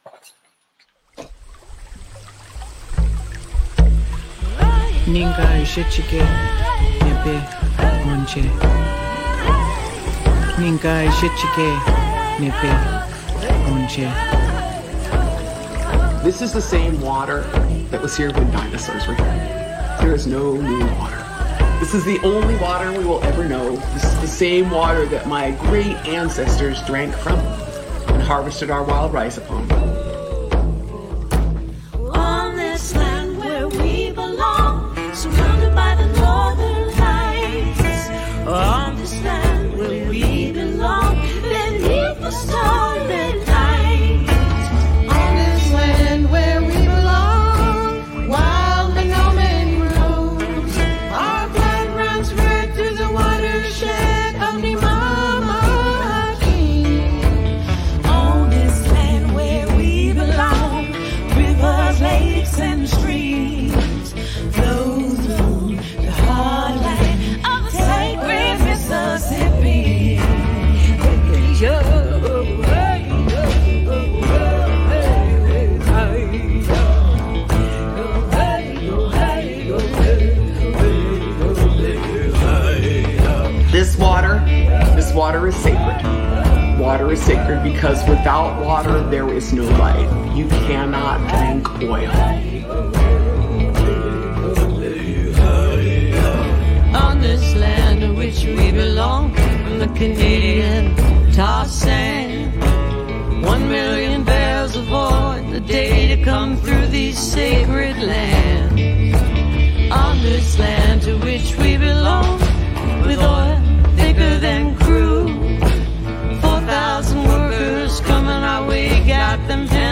(captured from facebook)
(studio version)